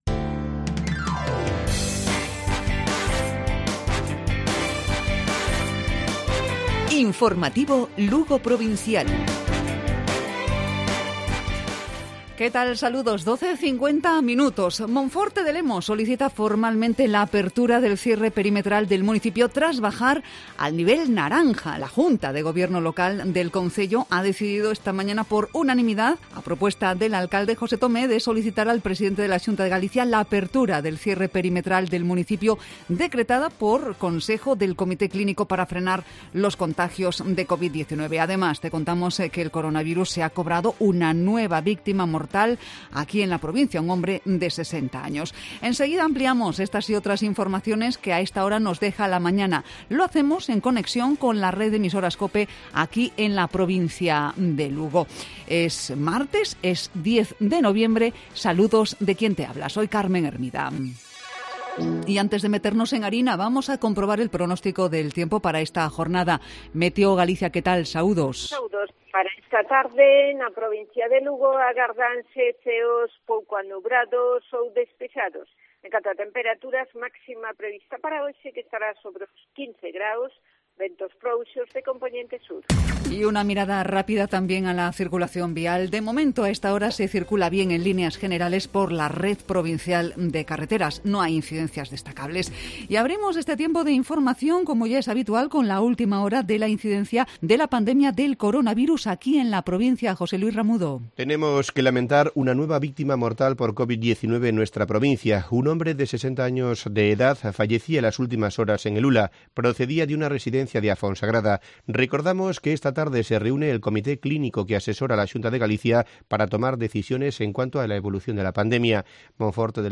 Informativo Provincial Cope Lugo.Martes, 10 de noviembre. 12,50 horas